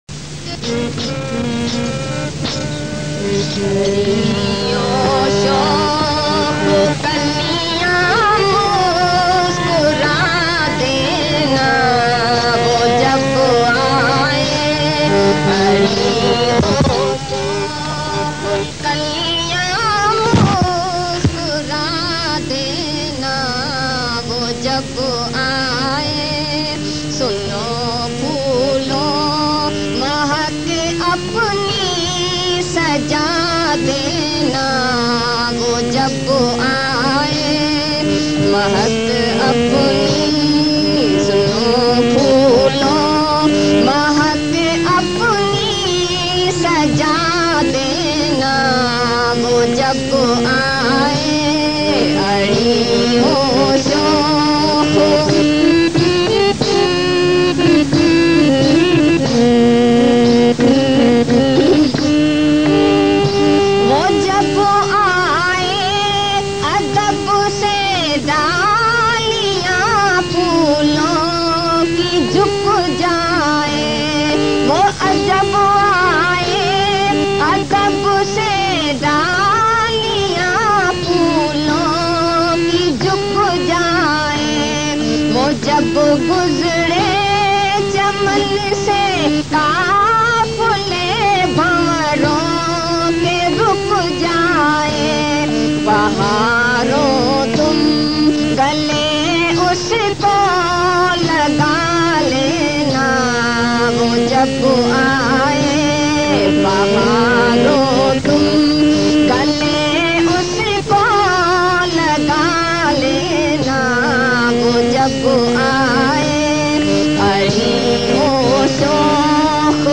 Ari-O-Shokh-Kaliyaan-Muskura-Dena-Wo-Jab-Aaye-Bhajan.mp3